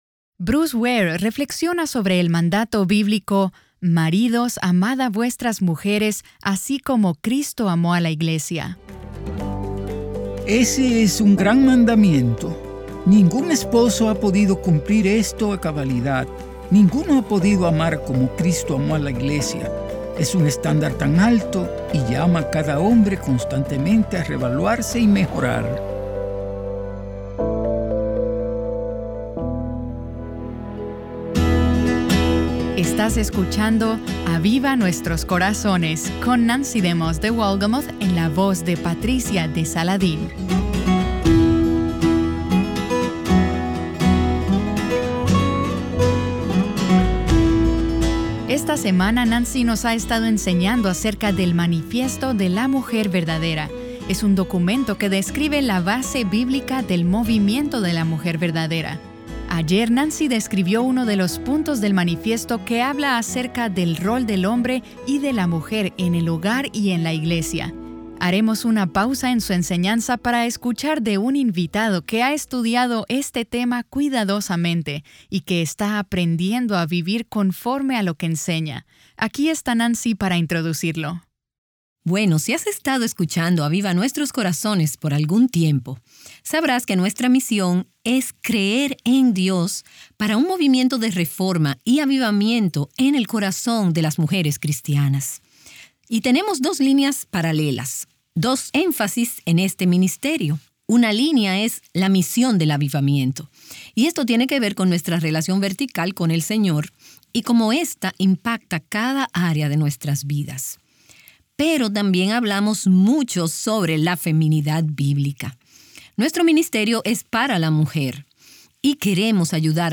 Escuchemos de un invitado que ha estudiado el rol del hombre y de la mujer en el hogar y en la iglesia cuidadosamente.